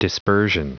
Prononciation du mot dispersion en anglais (fichier audio)
Prononciation du mot : dispersion